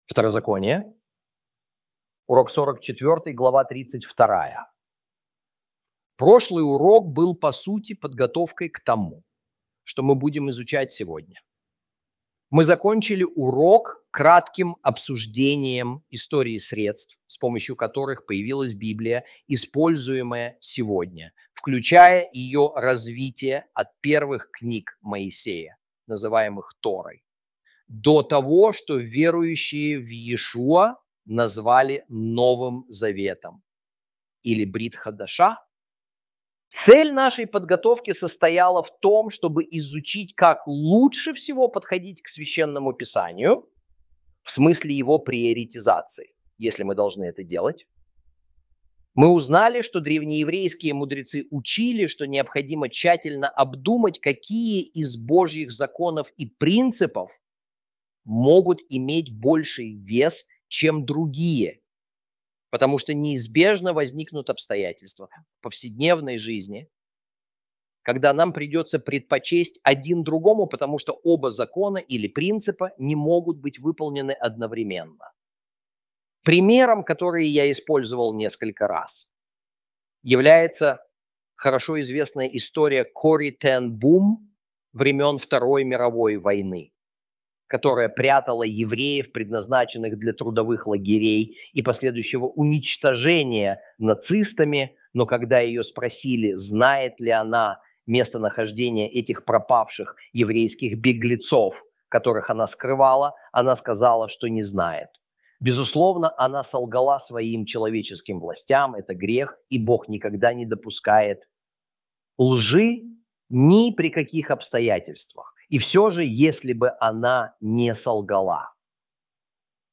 Video, audio and textual lessons